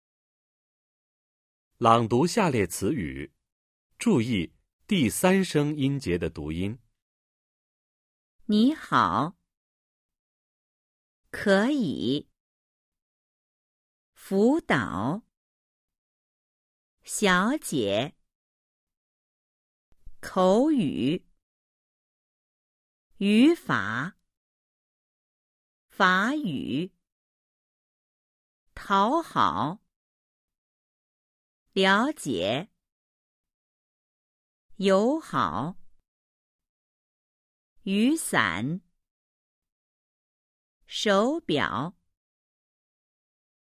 Sự biến đổi về thanh điệu khi hai âm tiết mang thanh 3 đi liền nhau